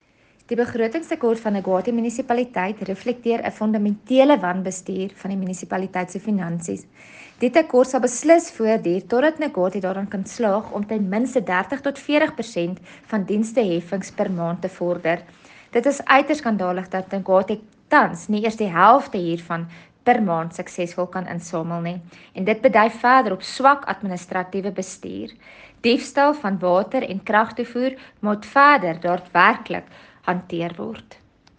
Afrikaans soundbites by Cllr Carina Serfontein.